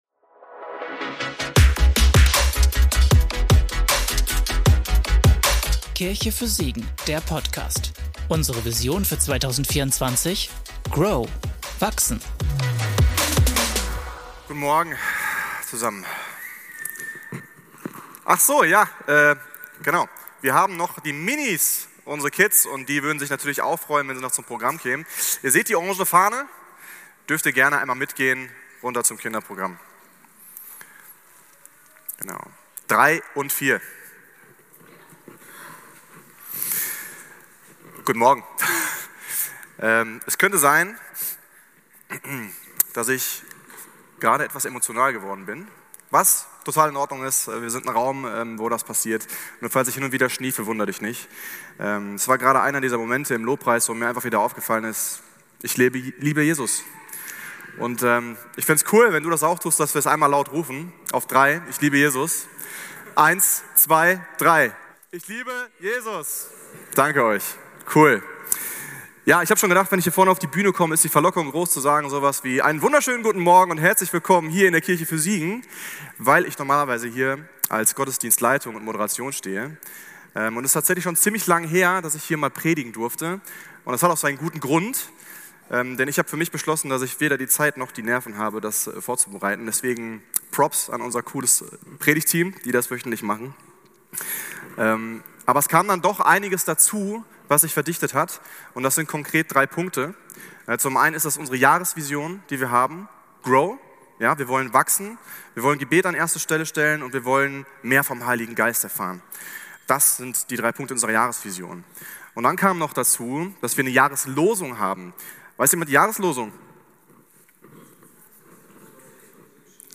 Behüte dein Herz - Predigtpodcast